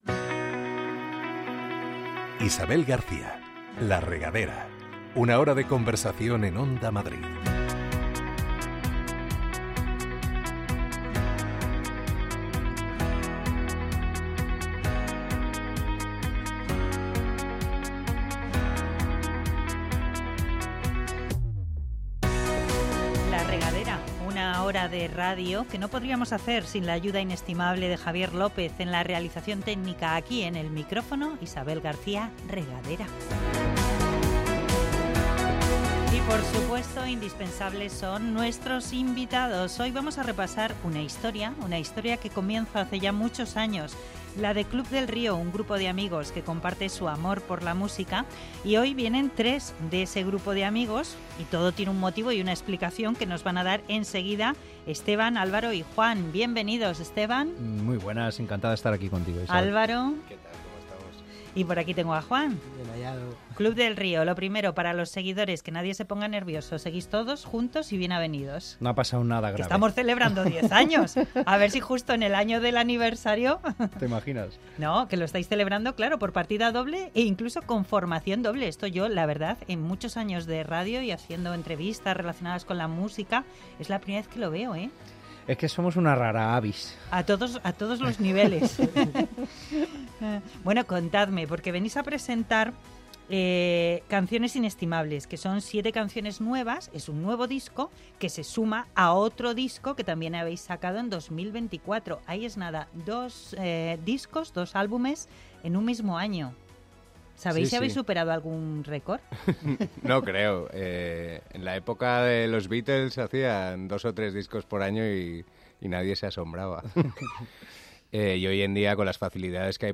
Este fin de semana en La Regadera de Onda Madrid charlamos con varios de los componentes del grupo musical Club del Río.
Son 7 nuevos temas en un formato casi acústico del que nos dan buena muestra en esta hora de radio.